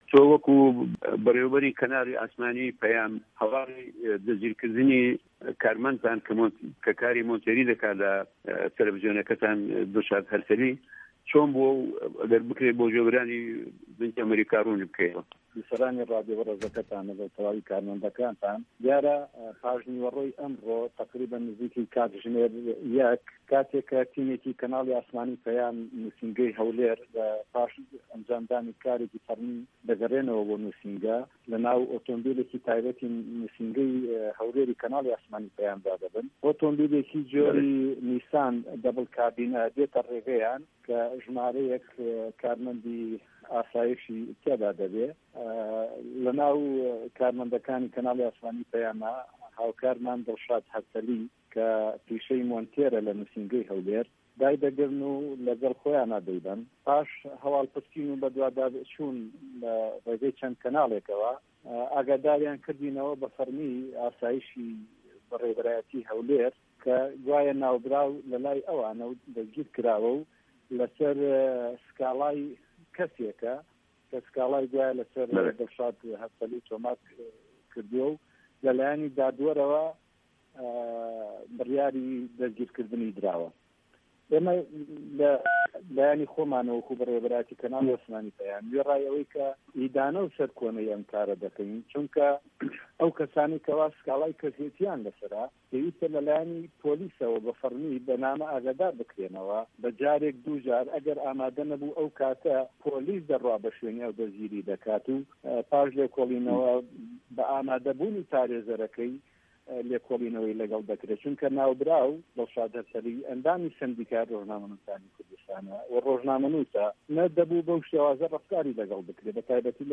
درێژەی وتووێژەکە لەم فایلە دەنگیـیەدایە.